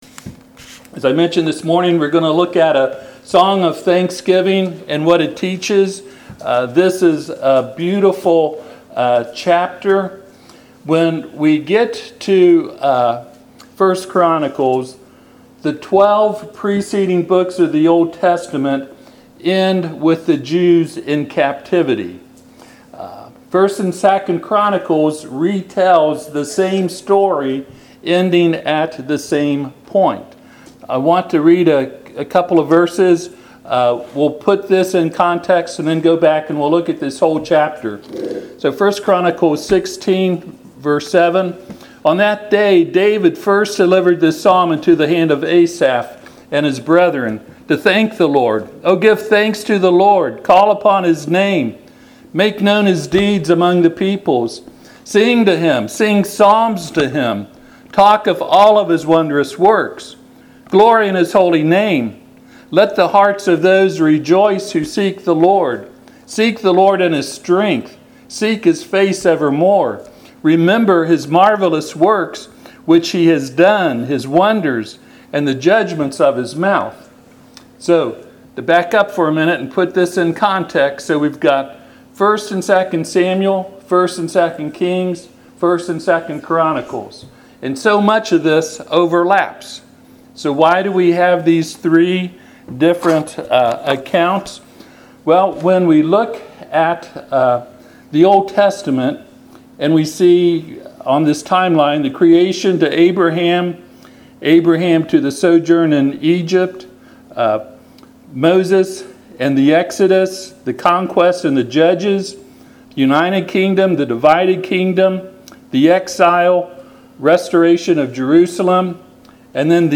Passage: 1 Chronicles 16:7-12 Service Type: Sunday PM